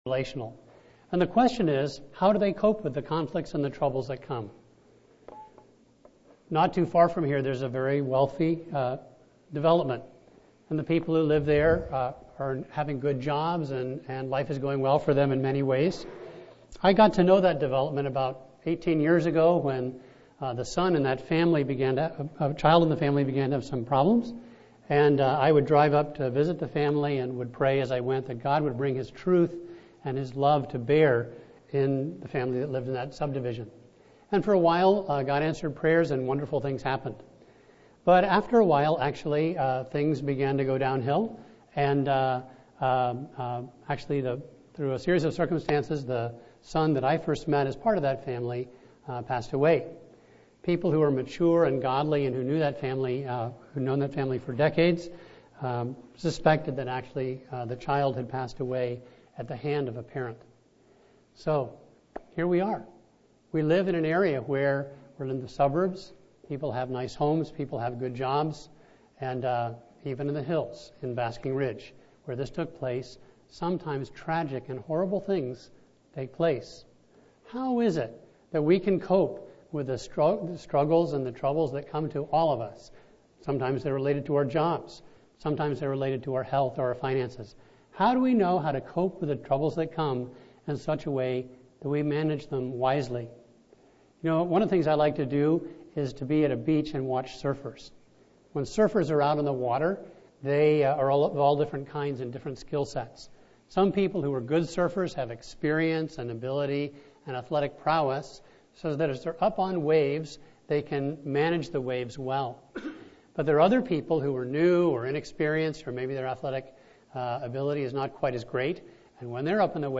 A message from the series "Job."